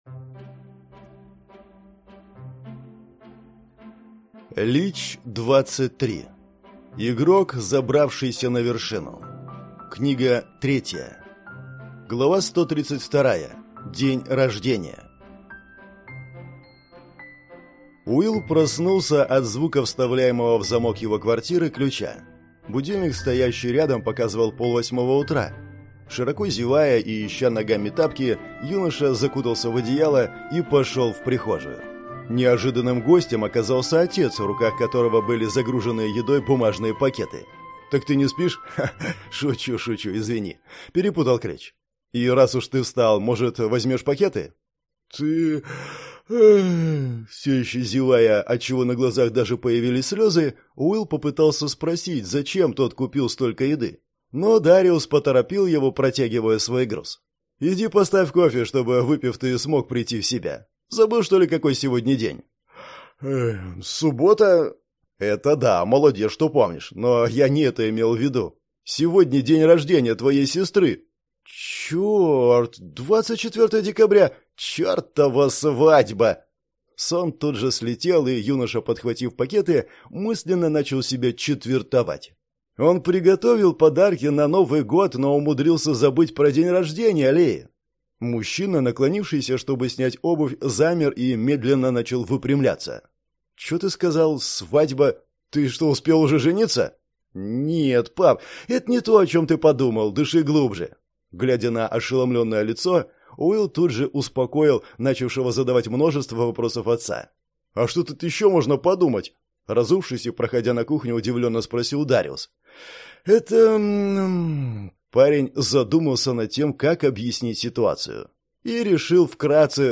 Аудиокнига Игрок, забравшийся на вершину. Книга 3 | Библиотека аудиокниг